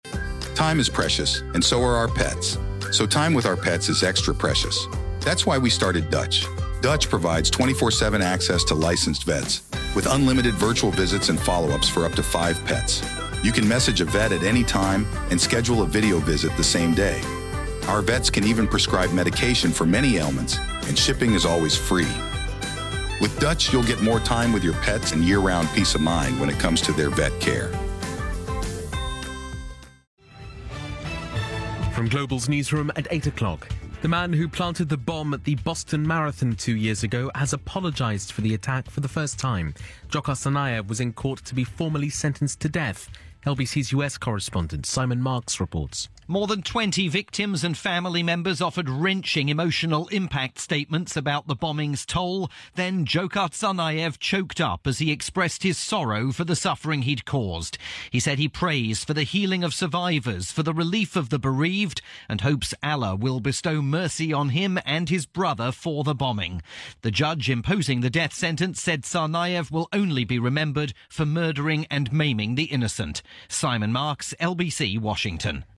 My coverage of Tsarnaev's dramatic court-room apology, via Britain's LBC Radio.